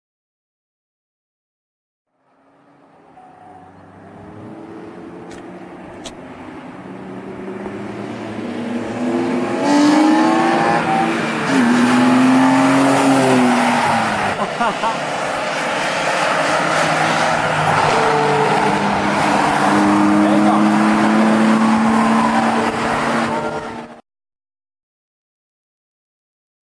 My ///M At Sears Point In Real Video ~780k